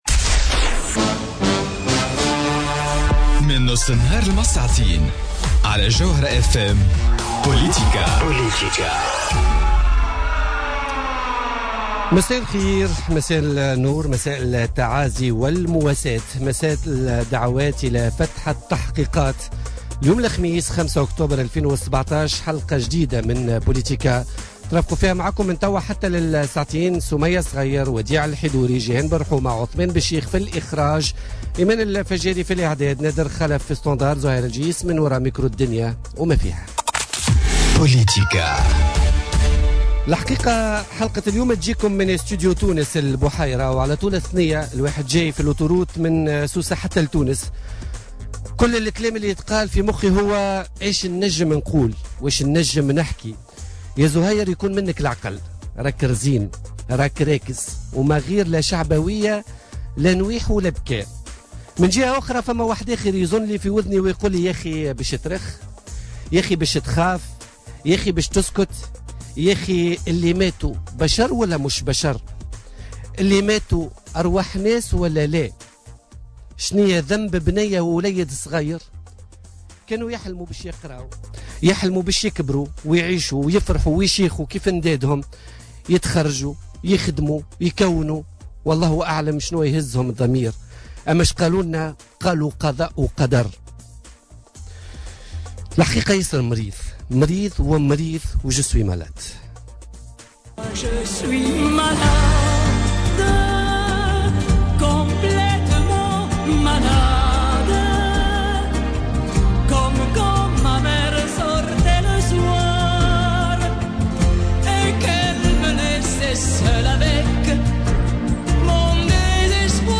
عبيد البريكي ضيف بوليتيكا